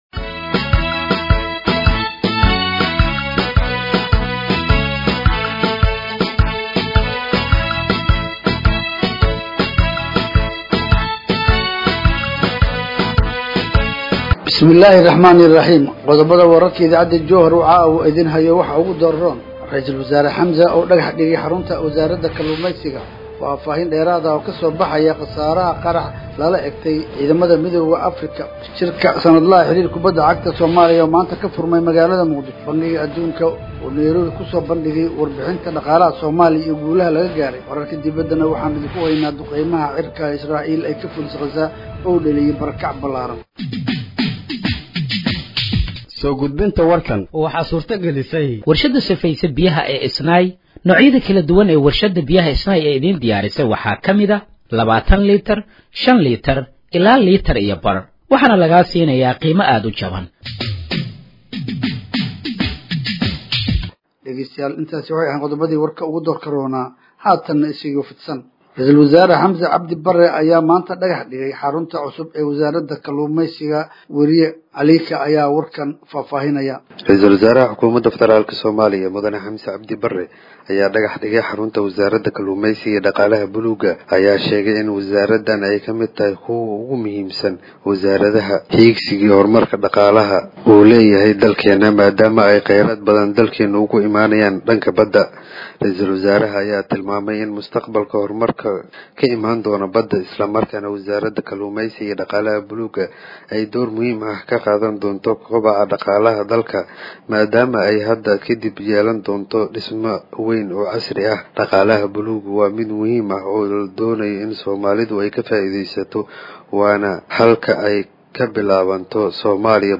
Halkaan Hoose ka Dhageeyso Warka Habeenimo ee Radiojowhar